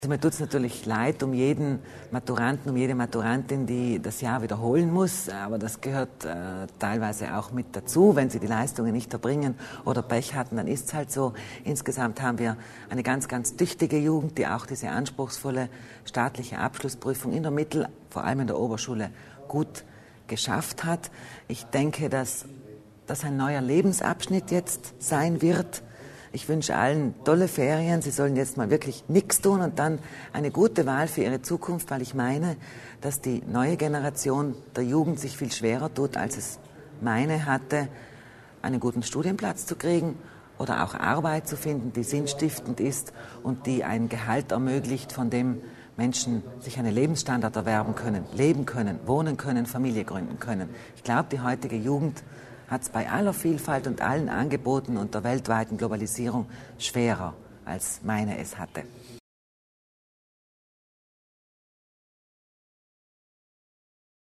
Landesrätin Kasslatter Mur zur Schulreform